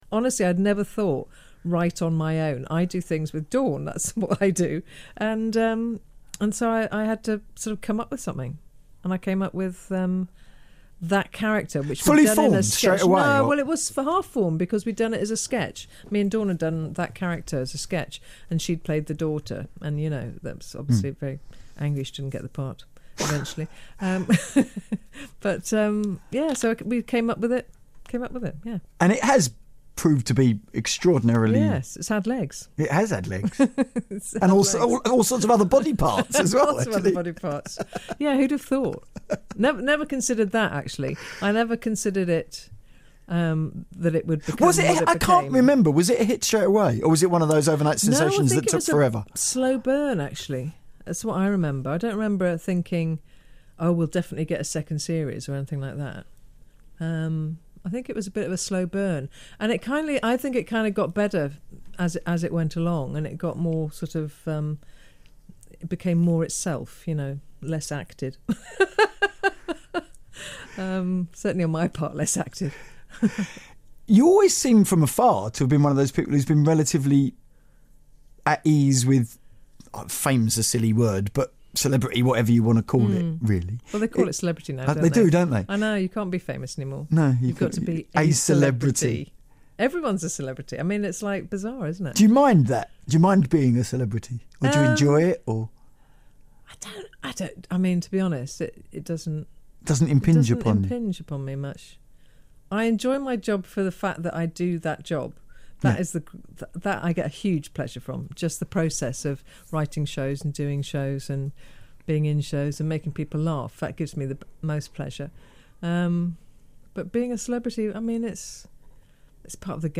This is a snippet of a longer interview.